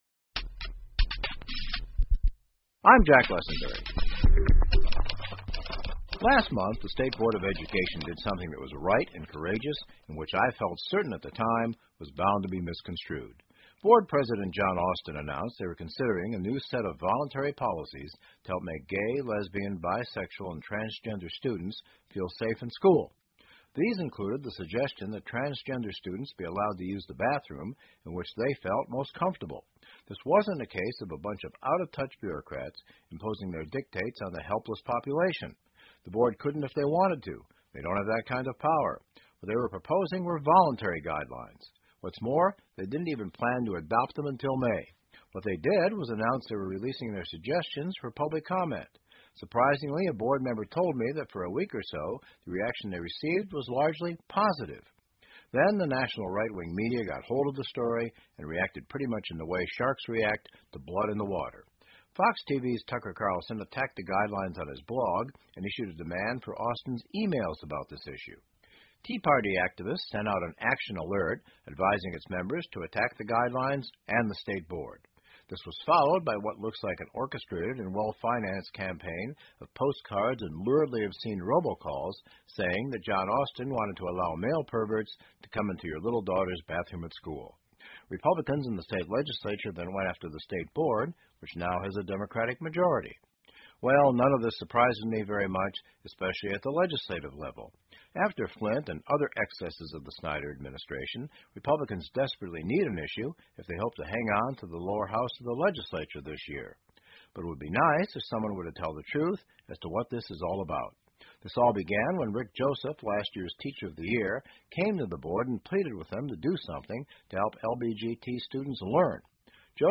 密歇根新闻广播 关于州教育局“LGBT”指南的真相 听力文件下载—在线英语听力室